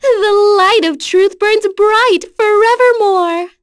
Yuria-Vox_Victory_b.wav